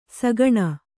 ♪ sagaṇa